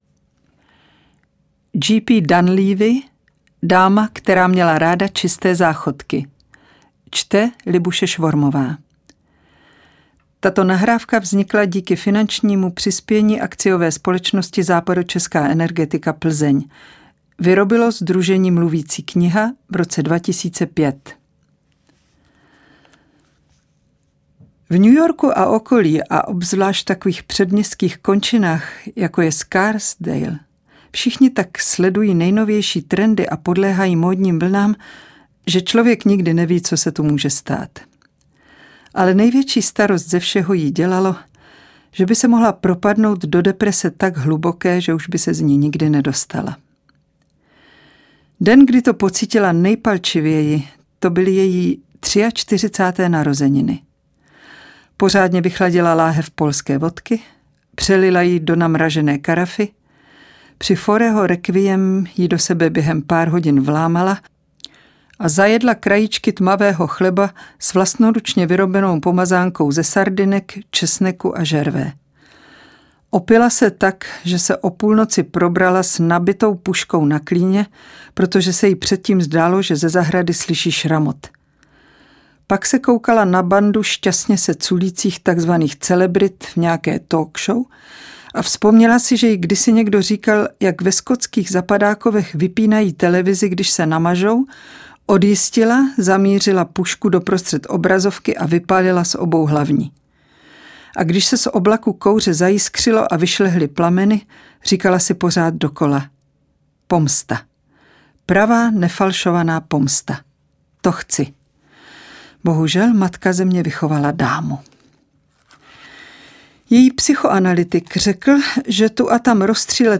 Čte: Libuše Svormová